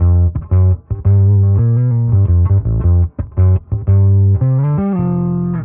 Index of /musicradar/dusty-funk-samples/Bass/85bpm
DF_PegBass_85-G.wav